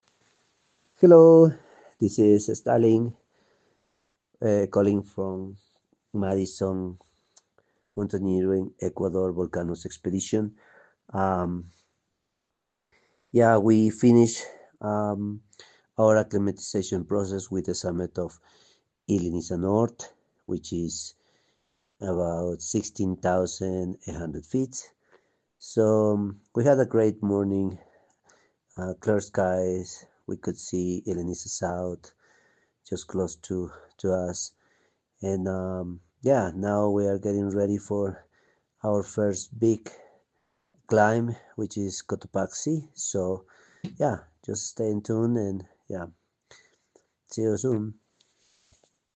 • Enable the skill and add to your flash briefing to hear our daily audio expedition updates on select expeditions.